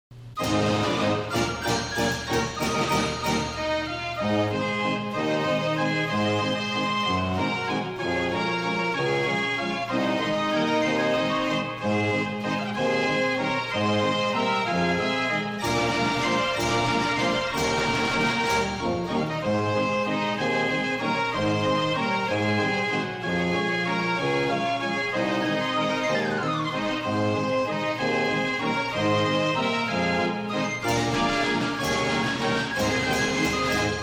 52 KEY FRENCH GASPARINI FAIR ORGAN